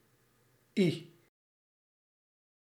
Ääntäminen
IPA : /hi/ US : IPA : [hi] UK